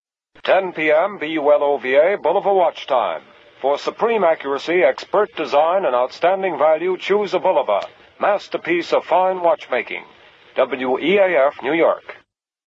Bulova emite el primer anuncio de radio del mundo en 1926
radio-bulova-150.mp3